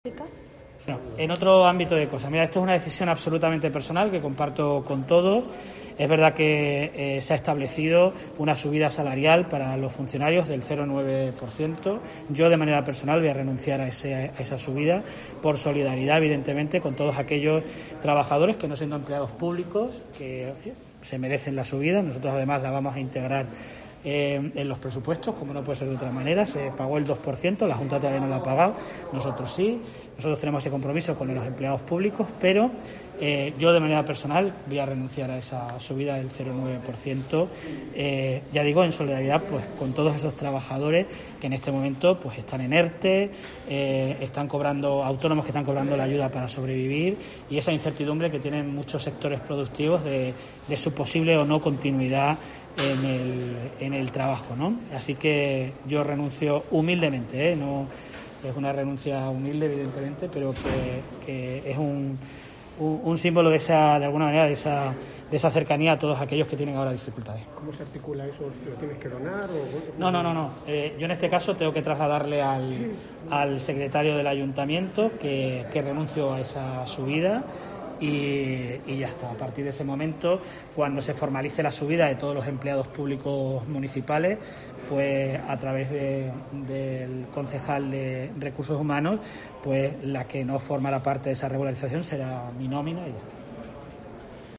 En declaraciones a los periodistas, el primer edil ha hecho pública una "decisión absolutamente personal" que ha decidido tomar "por solidaridad con todos esos trabajadores que, no siendo empleados públicos, se merecen la subida".